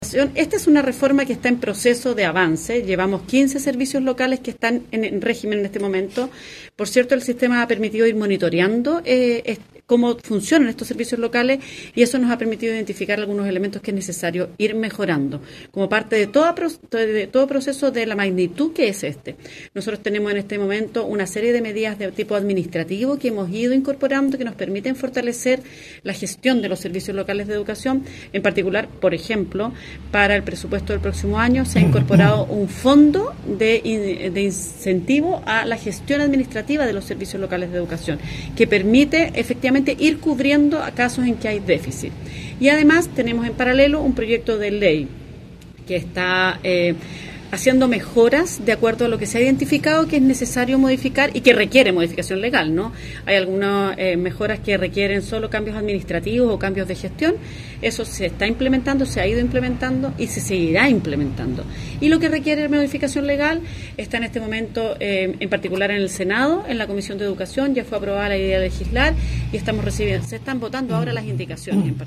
La subsecretaria de Educación, Alejandra Arratia, manifestó que aquellas situaciones negativas que presentan en su desarrollo los SLEP, se han ido solucionando mediante ajustes administrativos o bien, por la vía parlamentaria.